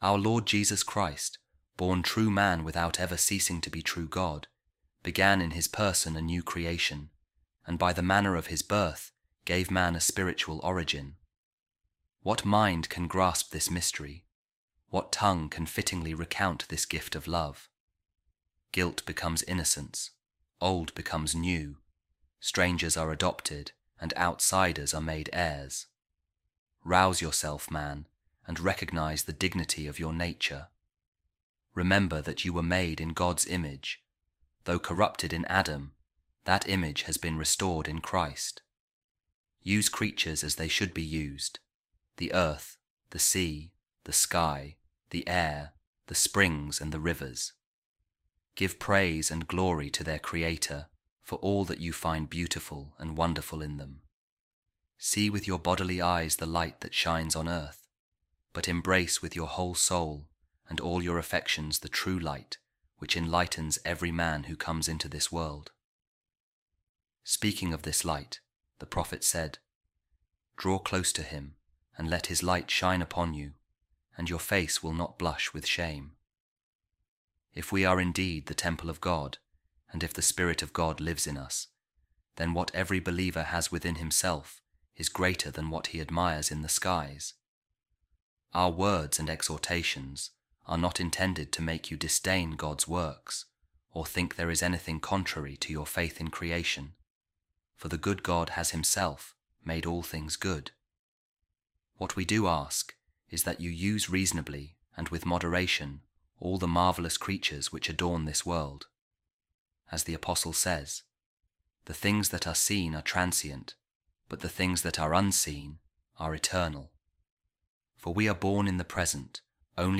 A Reading From The Sermons Of Pope Saint Leo The Great | Learn To Know The Dignity Of Your Nature